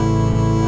quiet-hum-motor.mp3